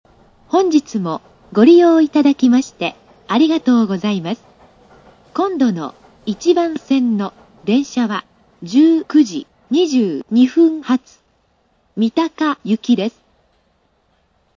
スピーカー：National
音質：A
予告放送　(71KB/14秒)